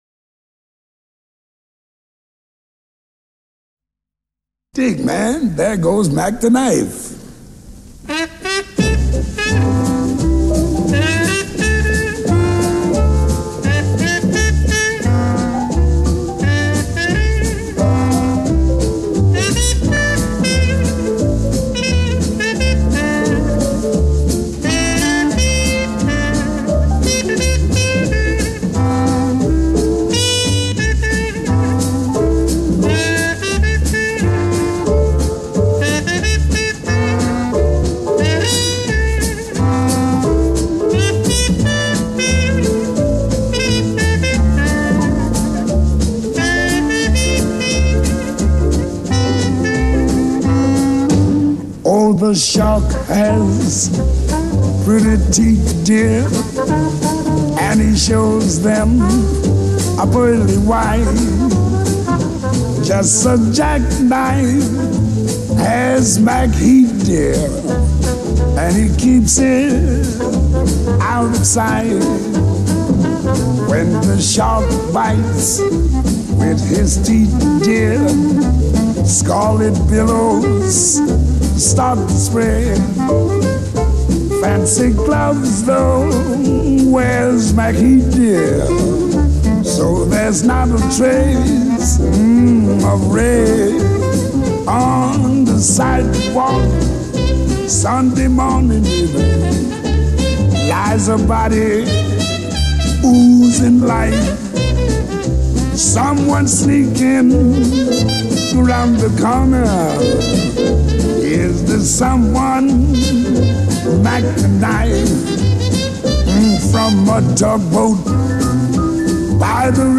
standard de jazz